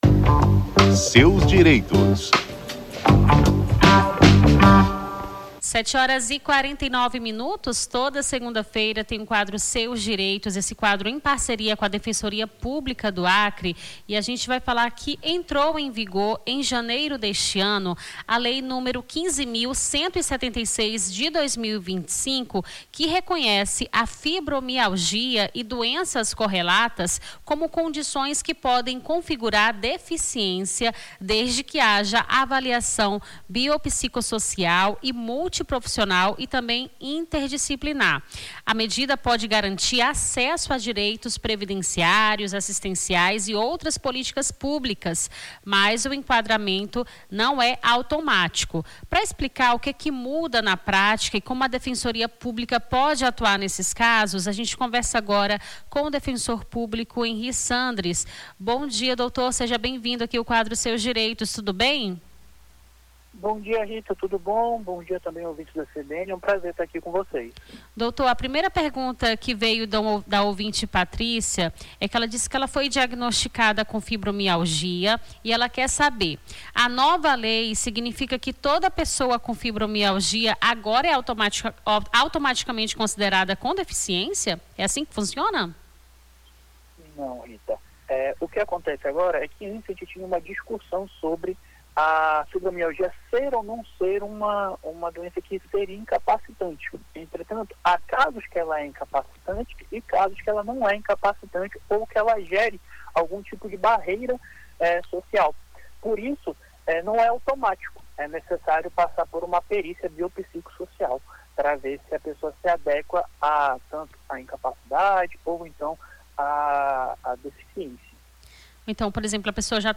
conversamos com o defensor público